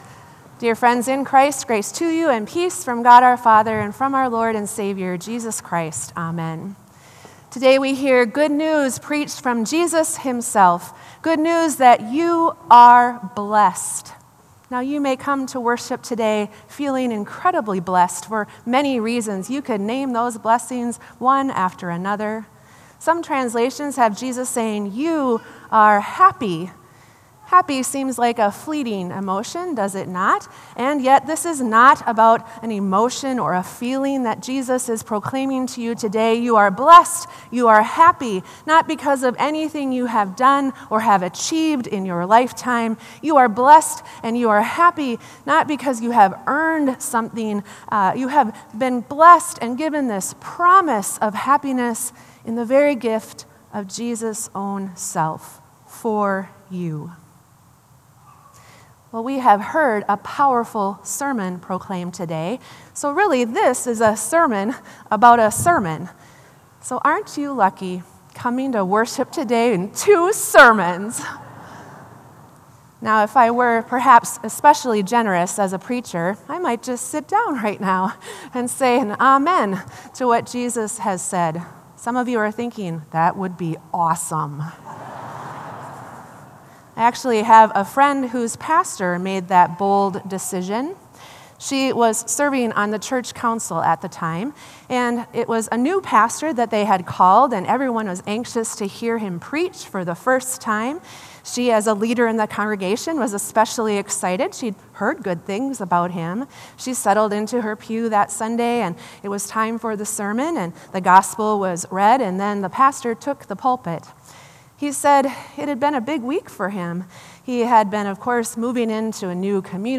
Sermon “When you have hope…”